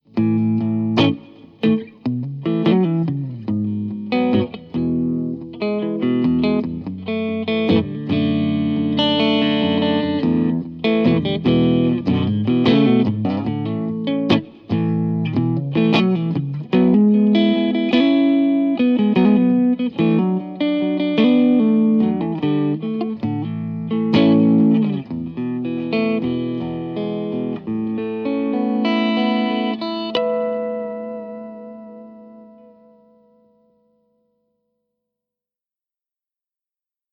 Strandberg Fusion micro grave